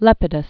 (lĕpĭ-dəs), Marcus Aemilius Died 13 BC.